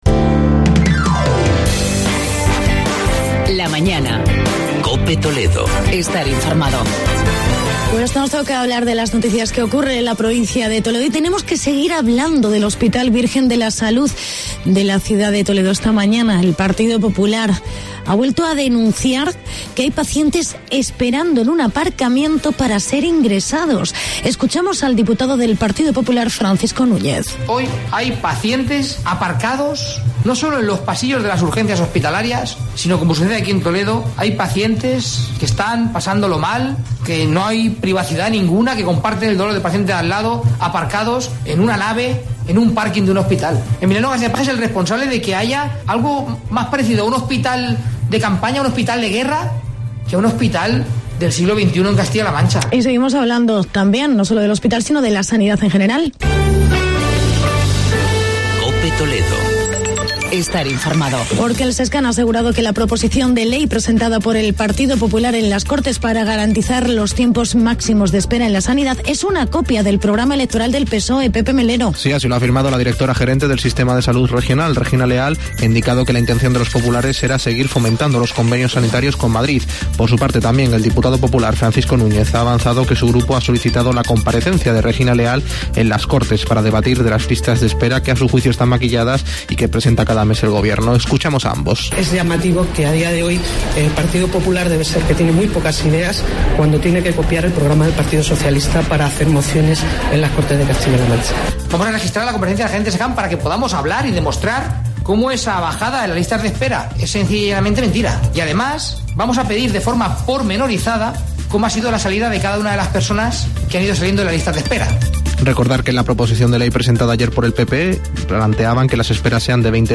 La Mañana de COPE TOLEDO en Fitur
Entrevistas